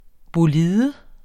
Udtale [ boˈliːðə ]